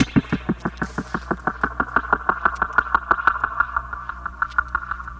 Back Alley Cat (Pecussion FX 04).wav